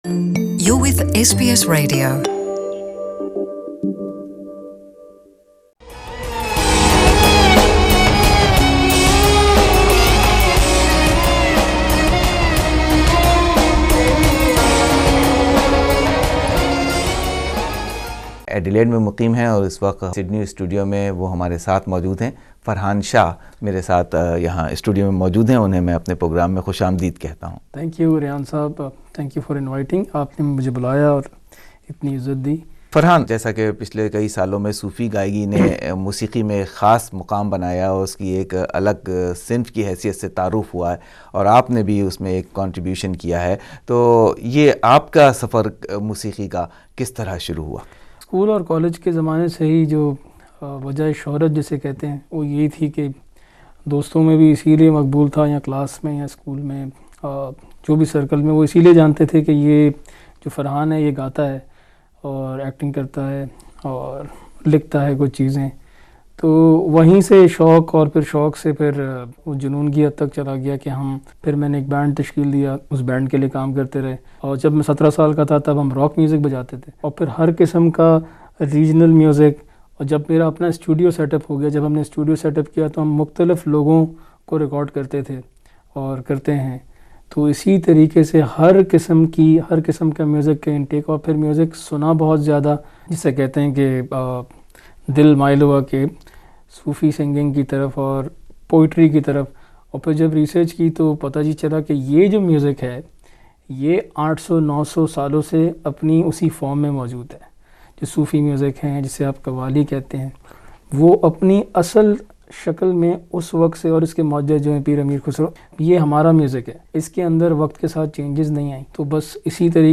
Guest of SBS Urdu